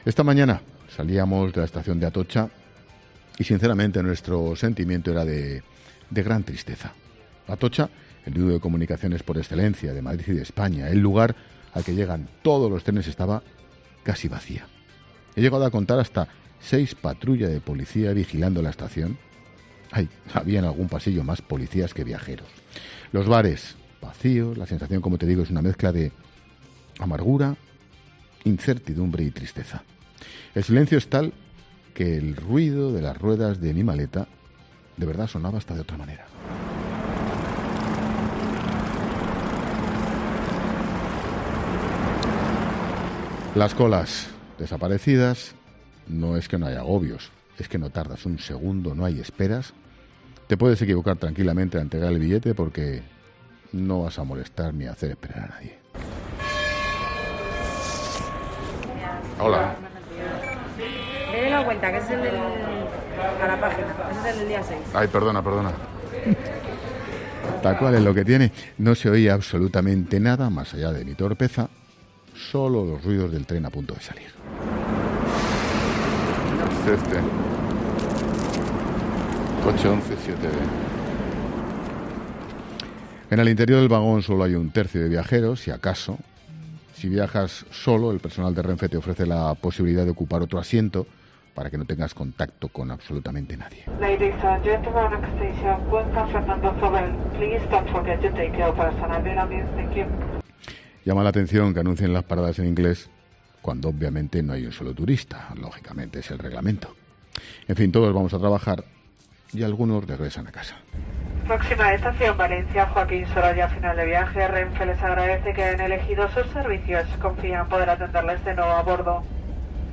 Ángel Expósito ha aprovechado el arranque de la tercera hora de 'La Linterna' de este lunes para relatar, en primera persona, cómo es, en estos momentos, viajar desde Madrid. El comunicador tenía que viajar a Valencia en tren, así que ha hecho un relato pormenorizado en antena sobre el escenario que se ha encontrado en la estación de Atocha.